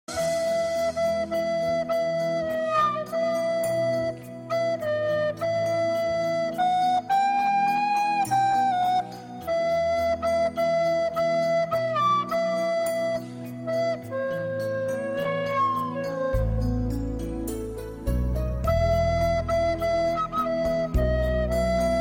Печальный напев дудки